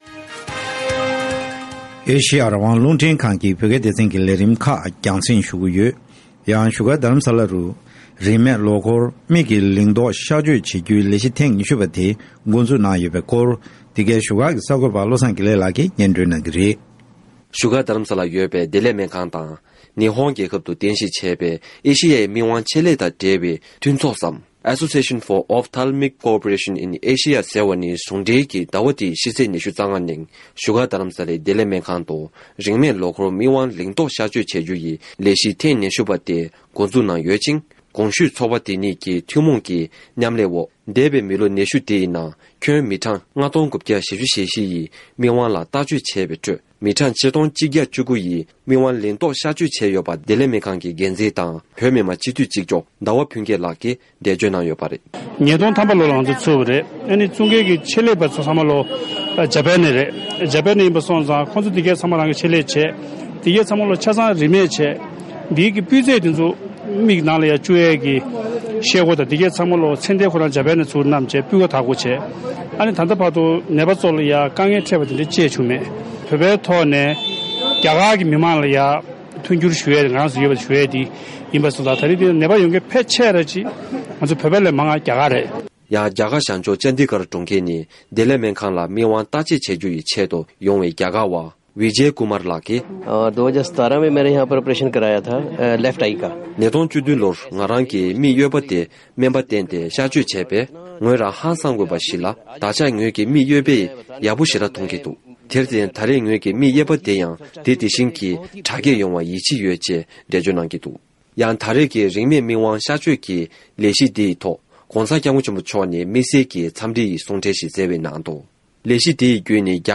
ས་གནས་ནས་གནས་ཚུལ་སྤེལ་བ་ནས་གསན་ཐུབ།
སྒྲ་ལྡན་གསར་འགྱུར། སྒྲ་ཕབ་ལེན།